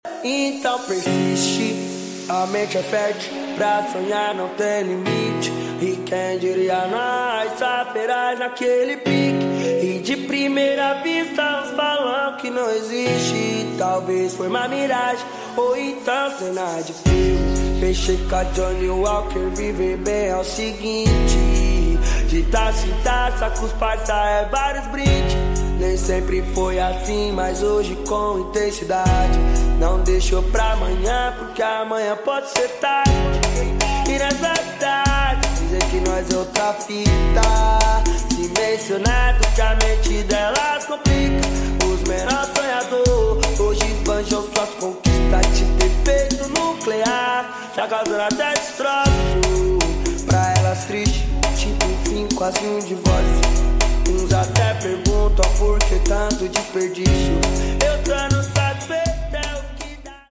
8D 🎧🎶